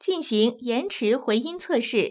ivr-to_do_a_fwd_echo_test.wav